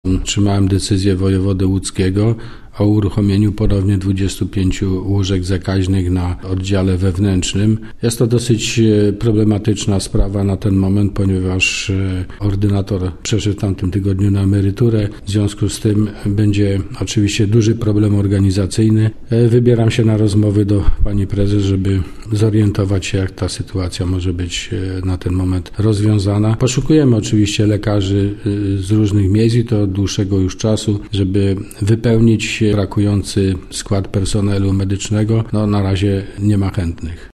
– komentował starosta powiatu wieruszowskiego, Andrzej Szymanek.